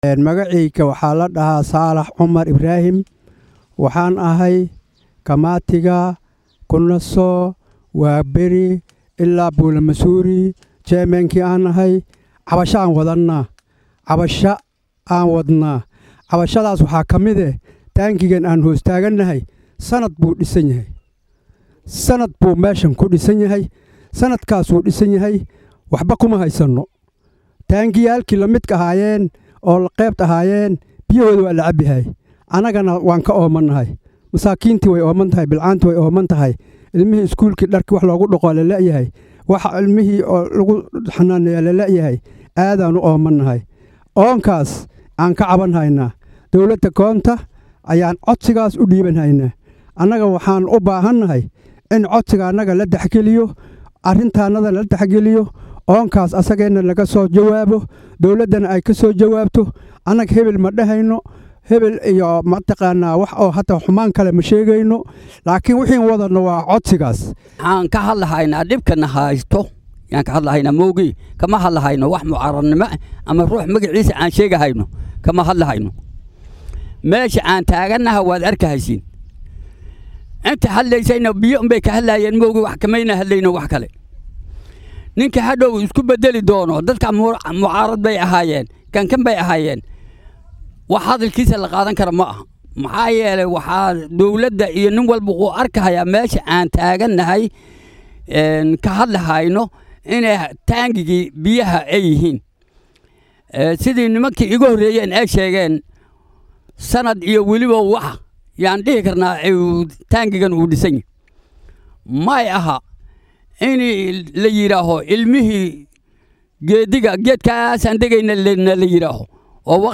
Dadweynaha ku nool xaafadaha mzuuri iyo kunaso ee waaxda waabari ee magaalada Garissa ayaa waxay ka cabanayaan biyo la’aan. Waxay sheegeen inay biyo la’aan yihiin muddo 10 sano ah. Qaar ka mid ah dadka ku nool xaafadahaasi oo warbaahinta la hadlay ayaa dareenkooda sidan u muujiyey.